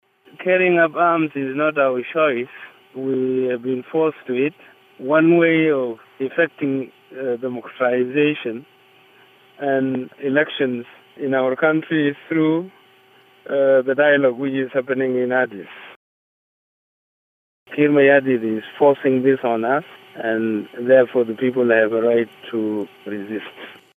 Part two of an interview with the former vice president of South Sudan, who fled into hiding after he was accused of organizing an alleged coup bid in December.